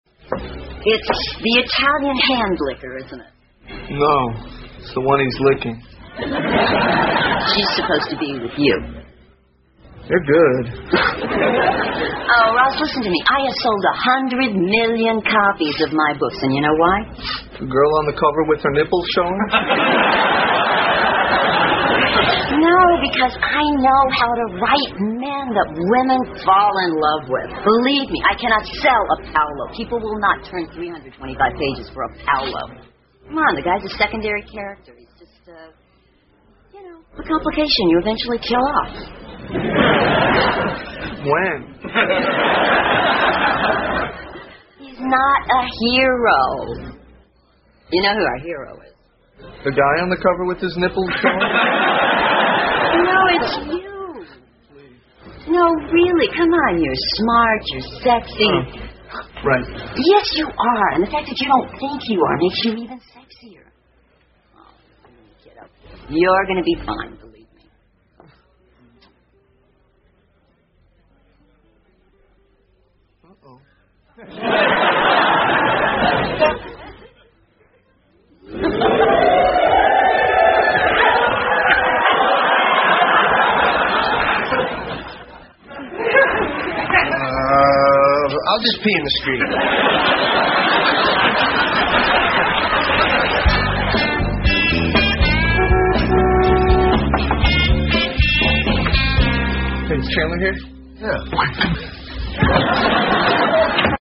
在线英语听力室老友记精校版第1季 第132期:钱德之母(6)的听力文件下载, 《老友记精校版》是美国乃至全世界最受欢迎的情景喜剧，一共拍摄了10季，以其幽默的对白和与现实生活的贴近吸引了无数的观众，精校版栏目搭配高音质音频与同步双语字幕，是练习提升英语听力水平，积累英语知识的好帮手。